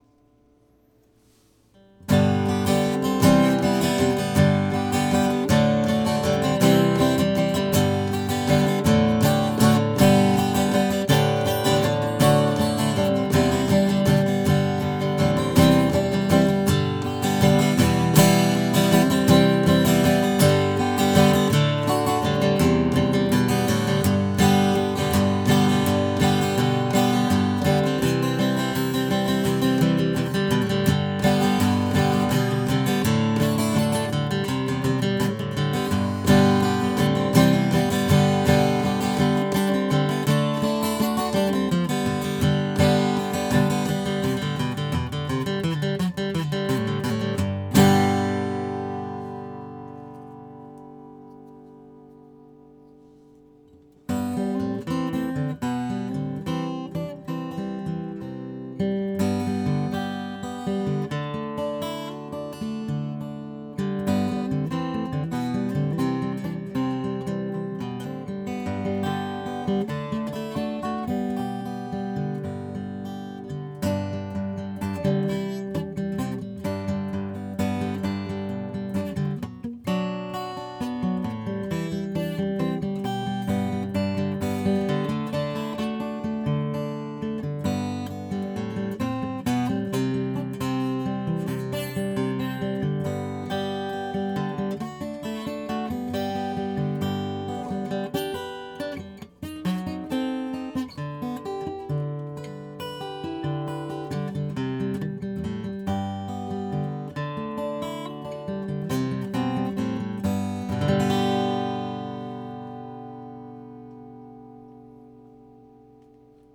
Нет наращивания, атака немедленная и сильная.
На нижнем торце практически ничего нет, а на верхнем мало.
Это гитара среднего уровня, точка.
Вот звуковой клип, который я сделал в первые 10 минут игры на гитаре: